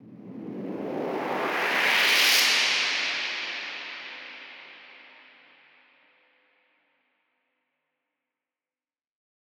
Index of /musicradar/shimmer-and-sparkle-samples/Filtered Noise Hits
SaS_NoiseFilterA-04.wav